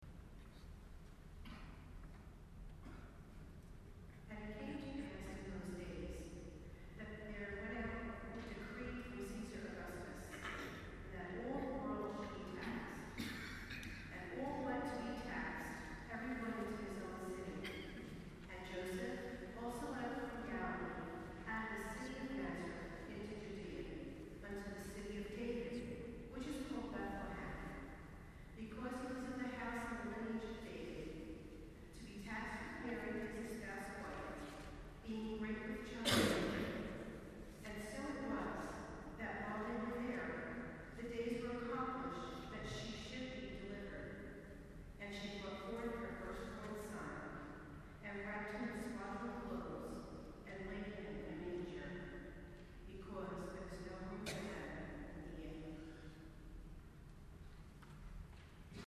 Lessons and Carols 2010
Our Lady of Mount Carmel Church, Newark New Jersey
14Lesson.mp3